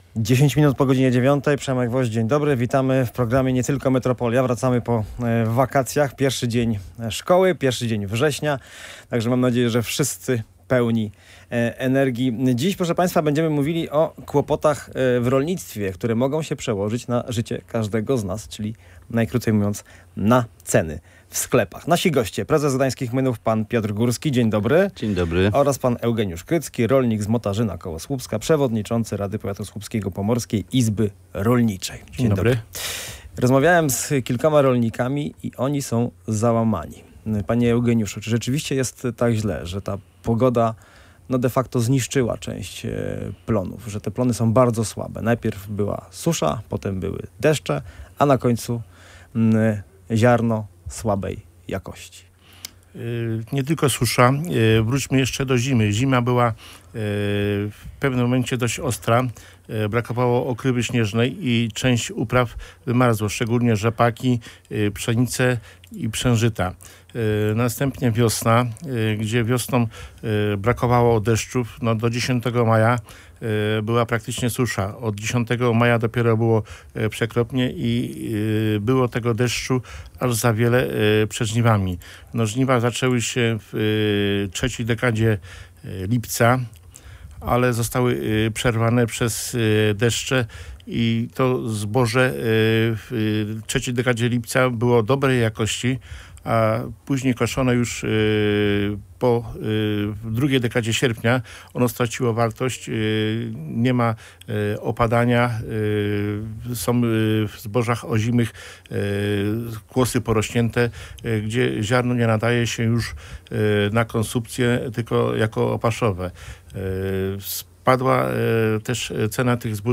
O kłopotach rolników i bardzo słabych plonach na Pomorzu rozmawiali goście pierwszej po wakacjach audycji Nie Tylko Metropolia.